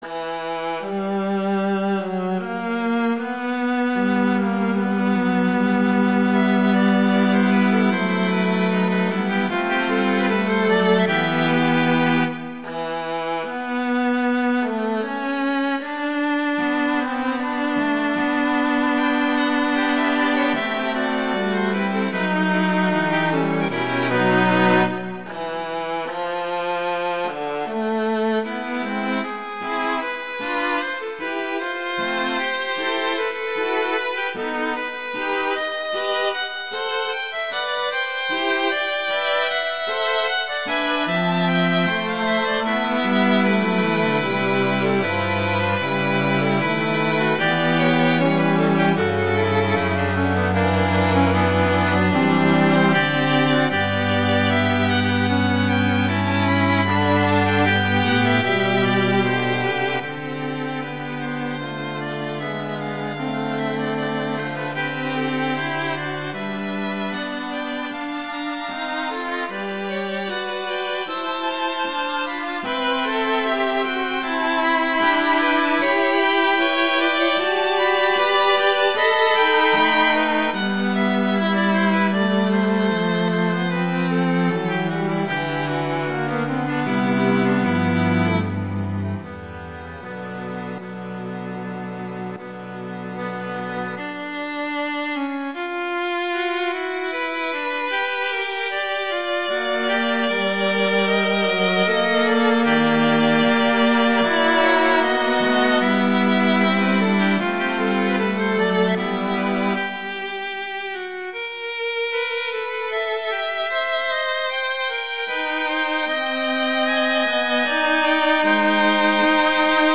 classical, french
E minor, A minor